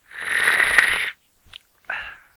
Drinking Coffee Sound Effect Free Download
Drinking Coffee